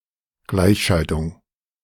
The Nazi term Gleichschaltung (German pronunciation: [ˈɡlaɪçʃaltʊŋ]